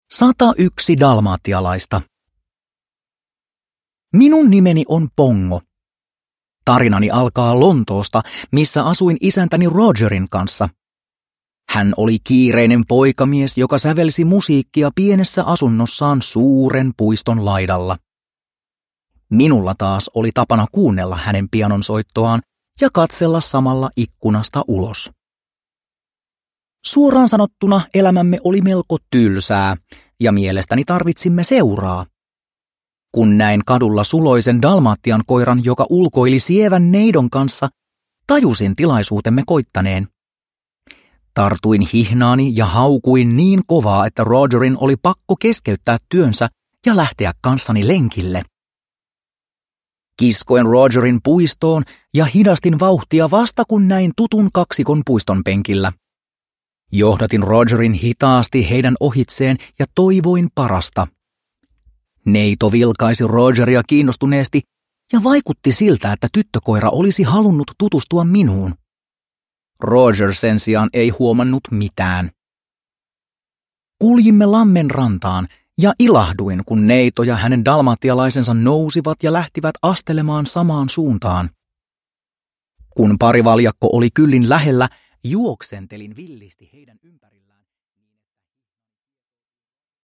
101 dalmatialaista – Ljudbok – Laddas ner